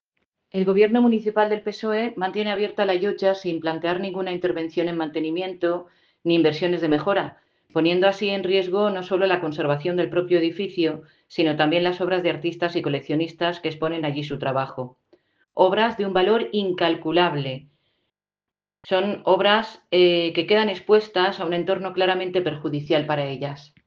corte-voz-isabel-balaguer-lotja-de-sant-jordi.ogg